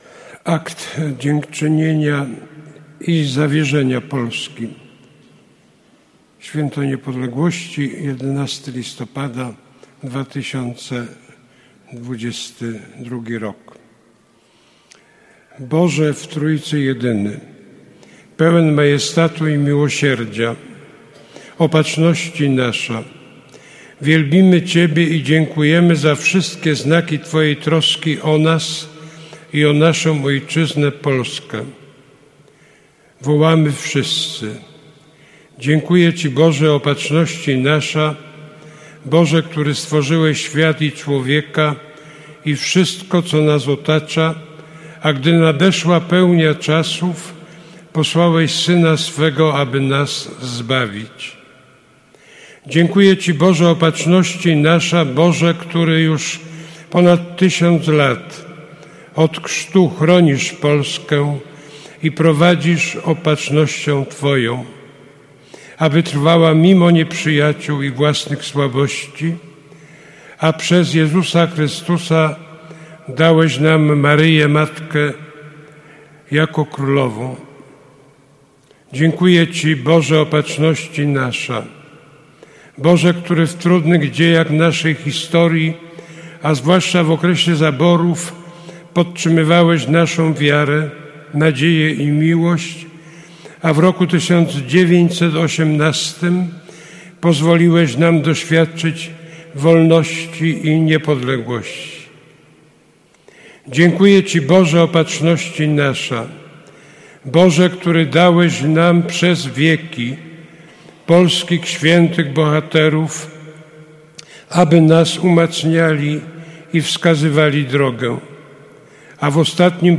Następnie wierni pod przewodnictwem księdza kardynała odmówili Akt Dziękczynienia i Zawierzenia Polski.